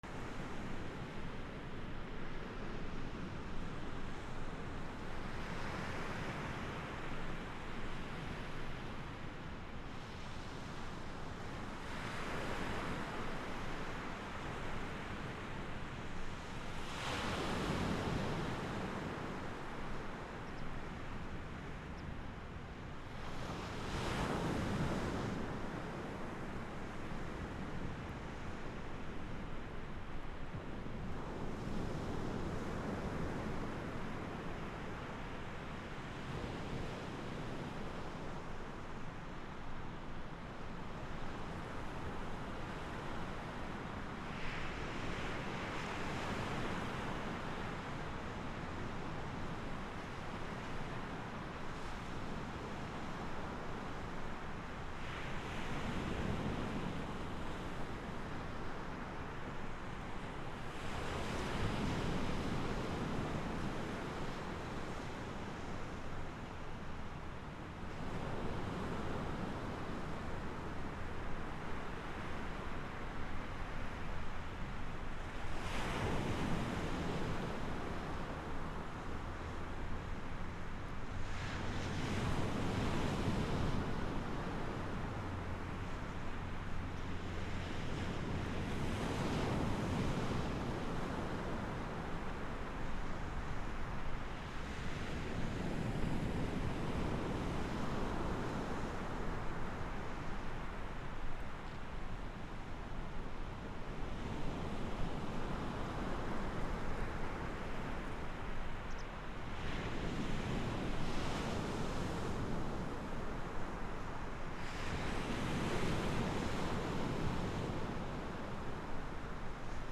波 約30m
/ B｜環境音(自然) / B-10 ｜波の音 / 波の音